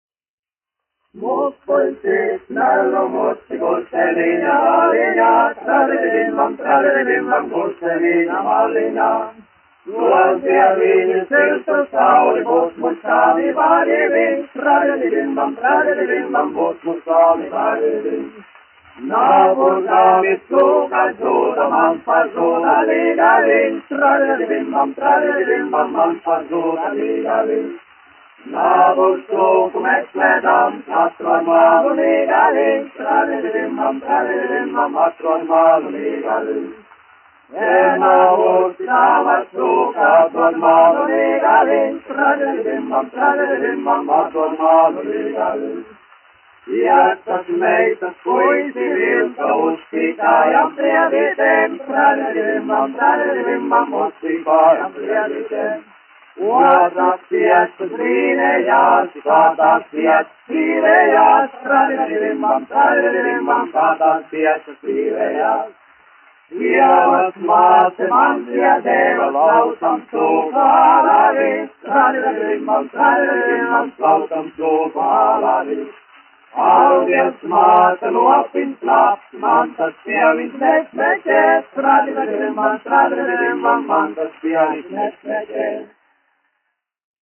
Piltenes prāģeri (mūzikas grupa), aranžētājs, izpildītājs
1 skpl. : analogs, 78 apgr/min, mono ; 25 cm
Latviešu tautasdziesmas
Vokālie seksteti